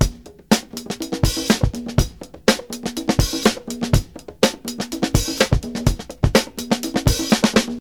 123 Bpm Drum Loop Sample F Key.wav
Free drum loop sample - kick tuned to the F note. Loudest frequency: 1845Hz
123-bpm-drum-loop-sample-f-key-pcs.ogg